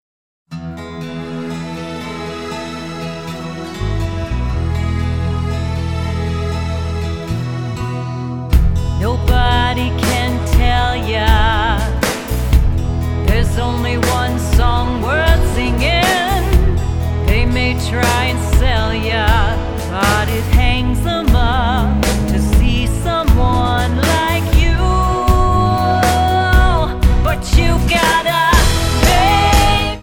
--> MP3 Demo abspielen...
Tonart:E Multifile (kein Sofortdownload.